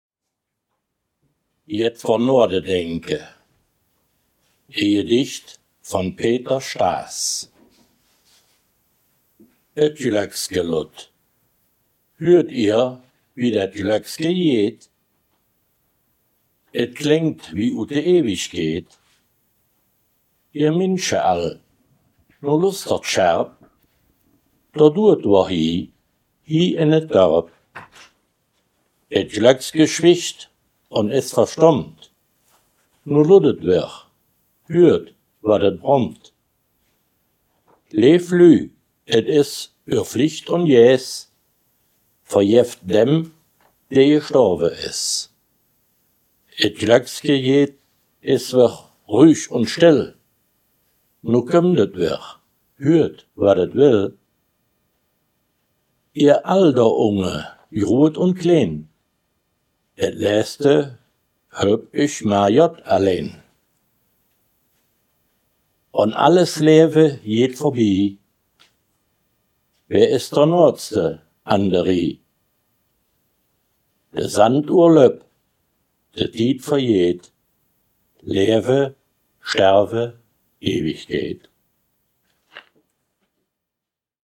Gangelter-Waldfeuchter-Platt
Gedicht